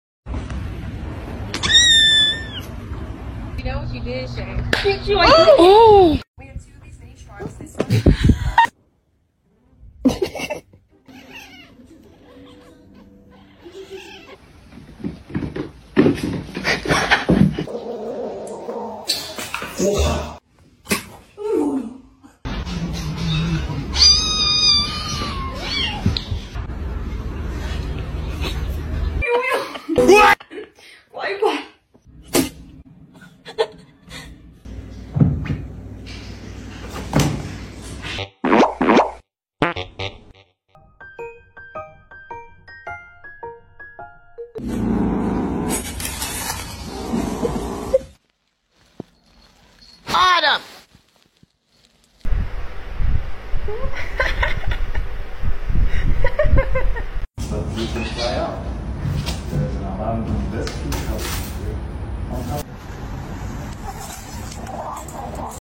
Very funny little kittens fighting sound effects free download